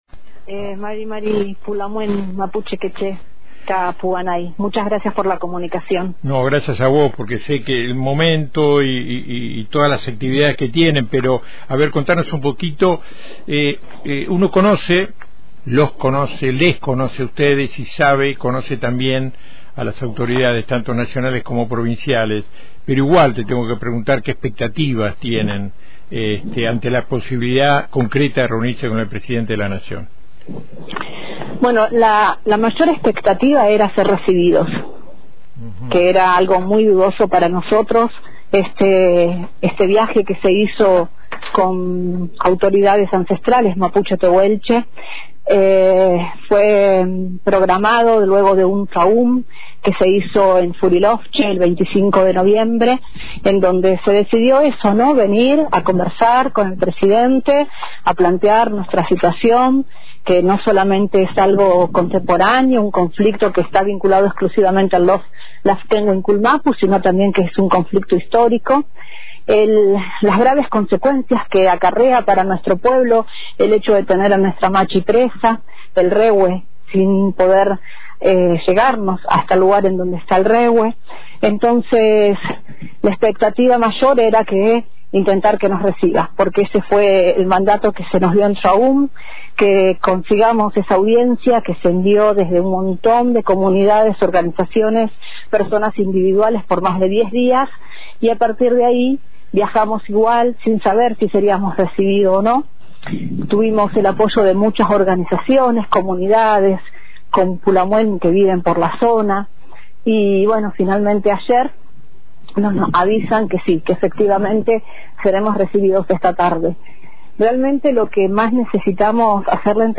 comentó en los micrófonos de Antena Libre